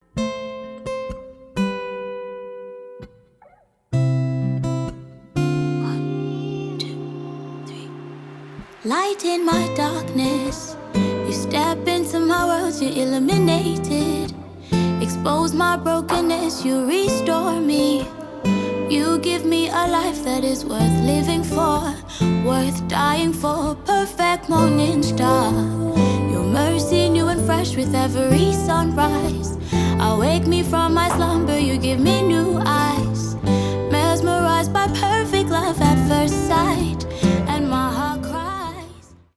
rnb , соул
поп , красивый женский голос , спокойные